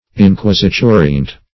Search Result for " inquisiturient" : The Collaborative International Dictionary of English v.0.48: Inquisiturient \In*quis`i*tu"ri*ent\, a. Inquisitorial.
inquisiturient.mp3